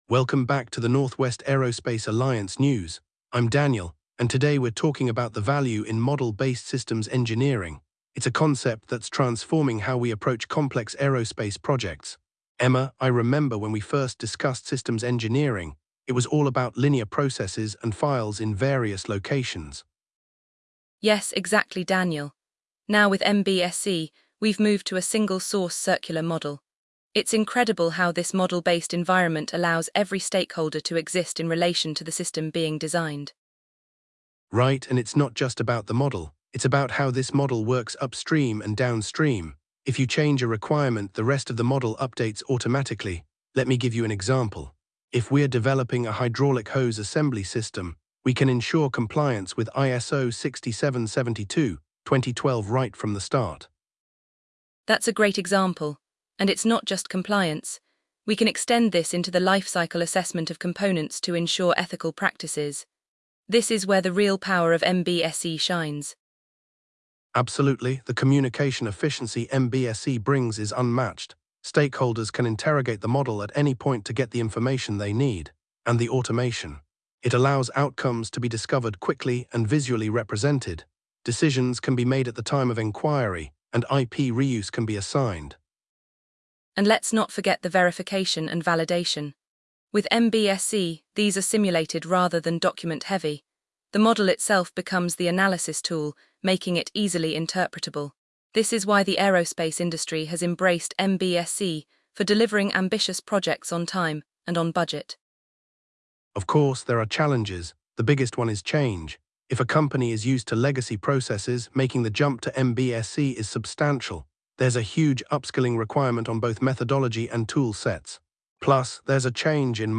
The conversation highlights the benefits of MBSE, including improved communication, lifecycle assessment, and efficient verification and validation through simulation. The hosts also address the challenges of adopting MBSE, such as the need for upskilling and a cultural shift within organizations.